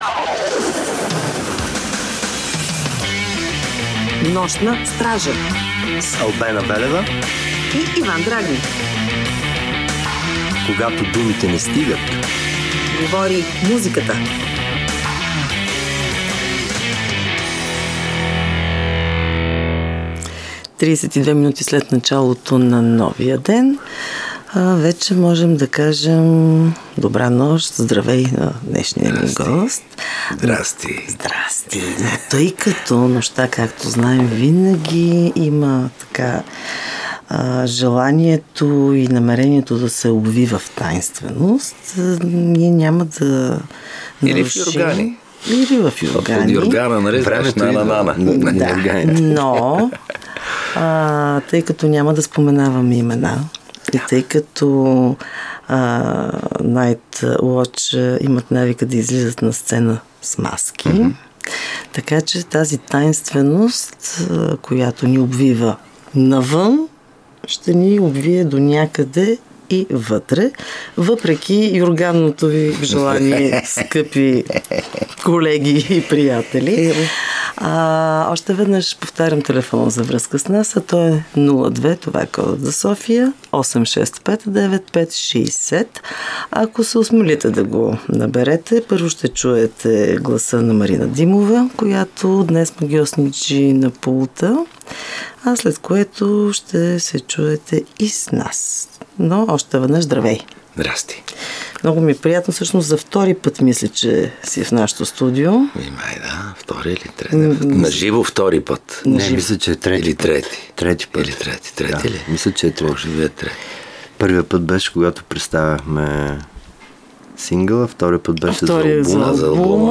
Четиримата маскирани членове на бандата, които според думите им са доста популярни в музикалните среди, издават песента в два варианта като ще чуете и двете версии в разговора ни. Какви са промените в групата, как върви работата по новия им албум и какво може да очаквате от тяхното Хелоуин парти може да разберете от нашето интервю с тях.
*Нощна Стража е предаване за рок музика, част от нощния блок на програма „Христо Ботев“ на БНР.